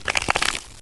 Heroes3_-_Skeleton_-_MoveSound.ogg